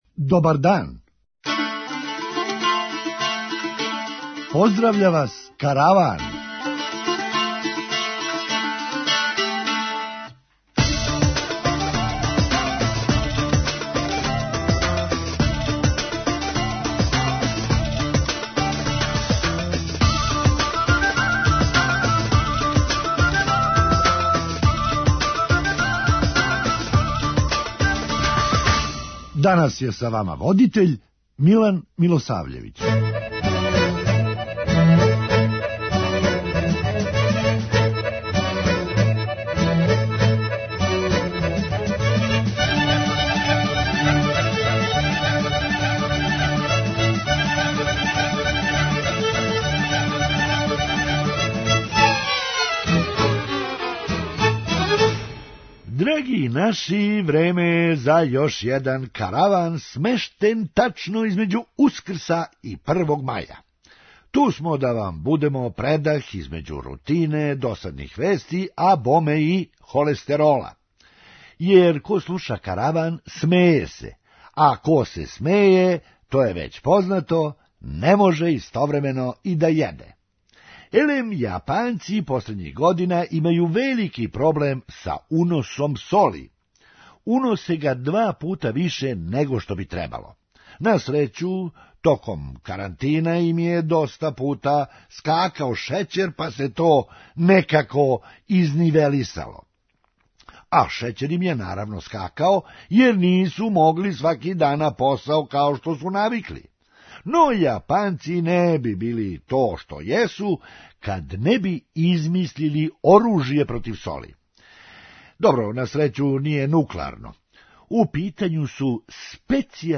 Хумористичка емисија
Познајући Бајдена, можда је ипак боље да понесе наочаре. преузми : 8.93 MB Караван Autor: Забавна редакција Радио Бeограда 1 Караван се креће ка својој дестинацији већ више од 50 година, увек добро натоварен актуелним хумором и изворним народним песмама.